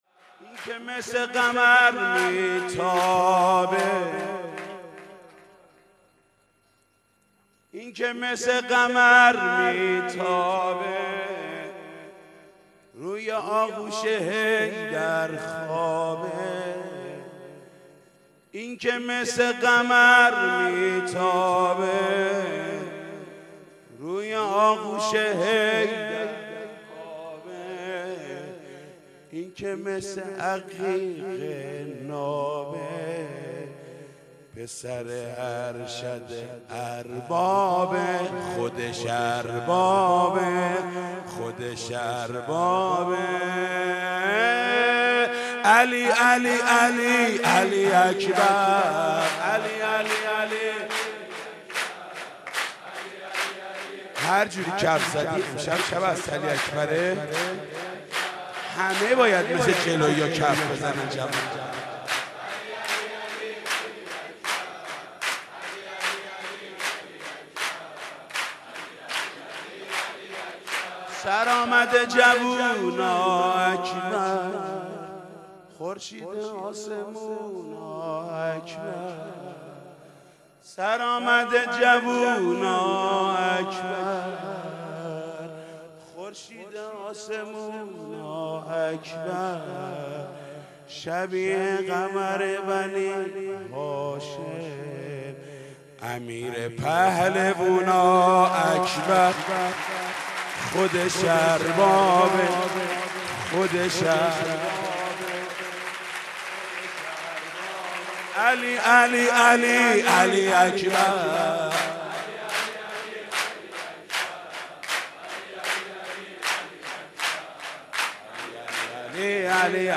مدح: اونی که مثل قمر میتابه